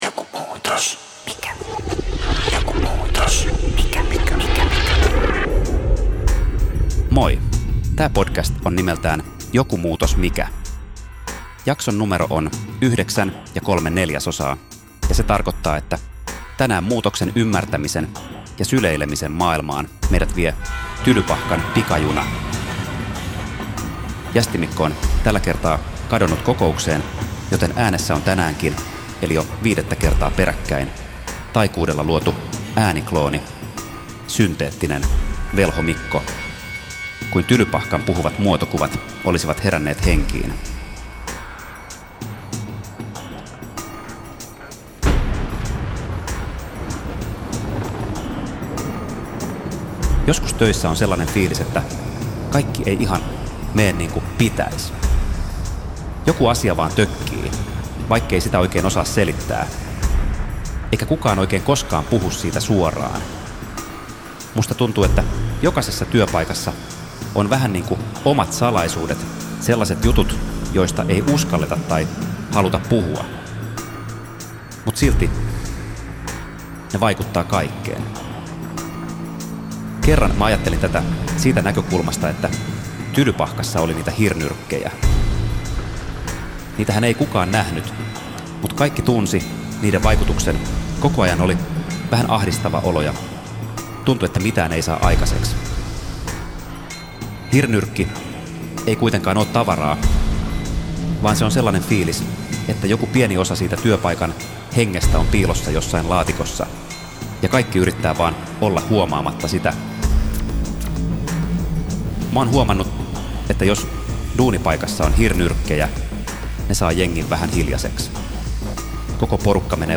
Tässä jaksossa äänessä on tekijän oma ääniklooni, synteettisesti tuotettu versio hänen puheestaan.